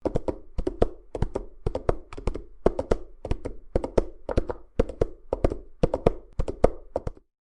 Single Horse Gallop Foley
Single Horse Gallop Foley is a free animals sound effect available for download in MP3 format.
Single Horse Gallop Foley.mp3